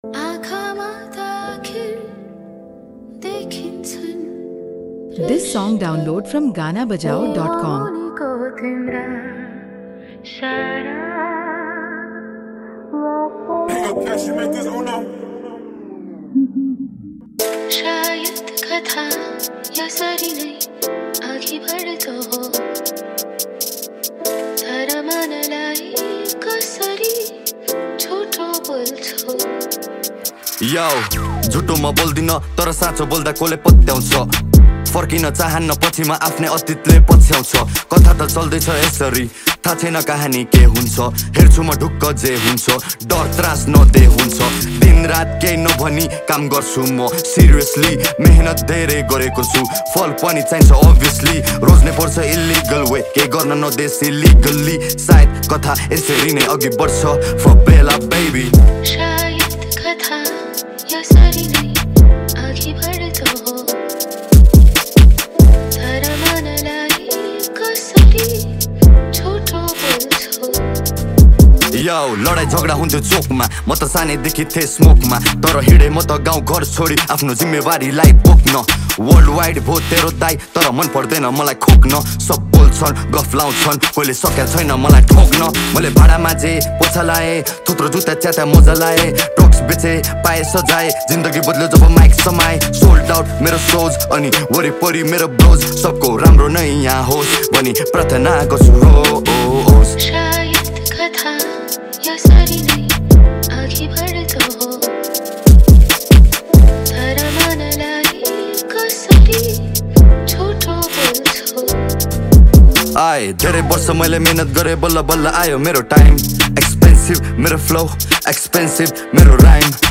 # Nepali Mp3 Rap Song